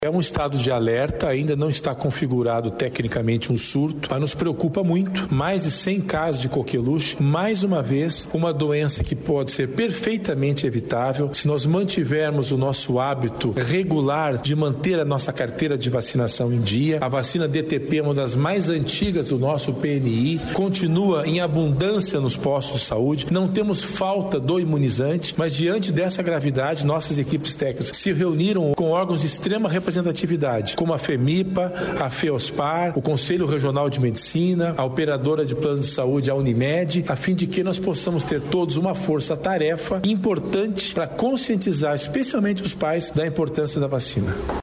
Sonora do secretário da Saúde, César Neves, sobre o combate ao coqueluche